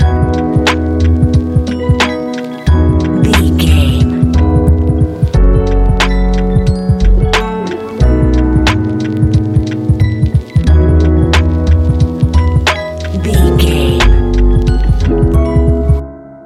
Ionian/Major
laid back
Lounge
sparse
new age
chilled electronica
ambient
atmospheric